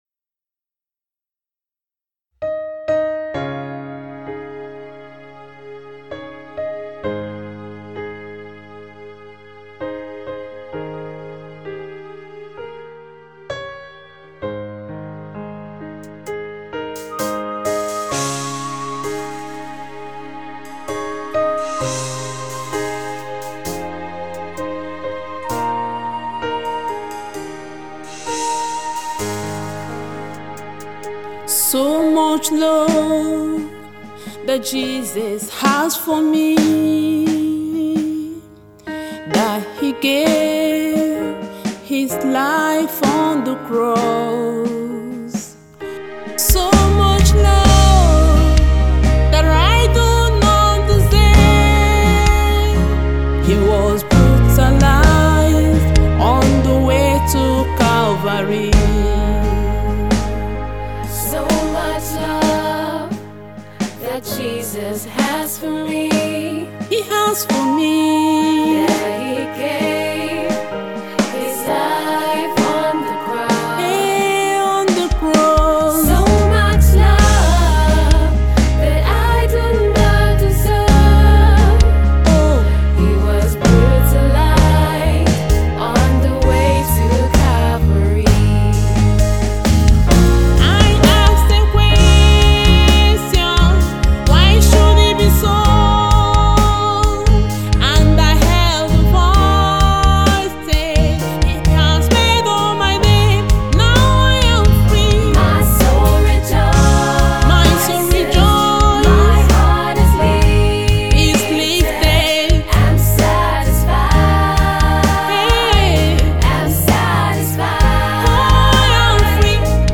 female gospel artist